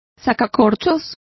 Complete with pronunciation of the translation of corkscrew.